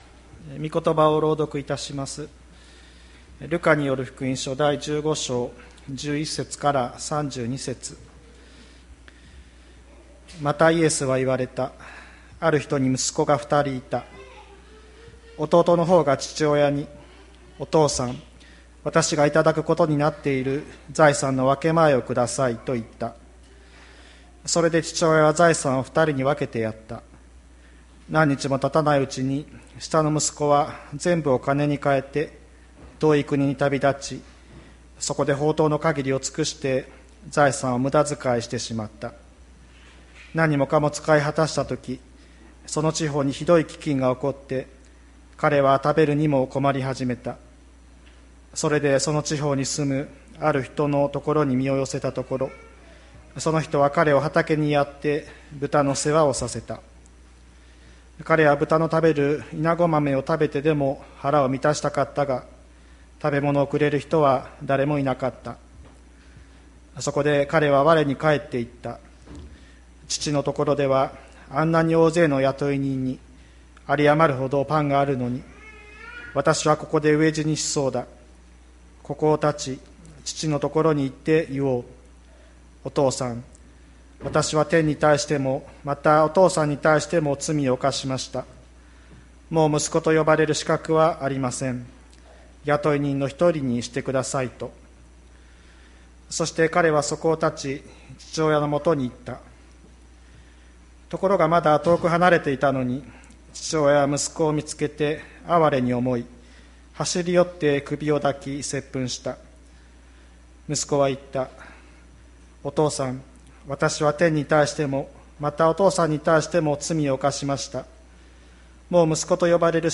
2021年08月08日朝の礼拝「神の常識と人間の良心」吹田市千里山のキリスト教会
千里山教会 2021年08月08日の礼拝メッセージ。 先週に引き続き、「放蕩息子のたとえ」と呼ばれる聖書物語を聞きました。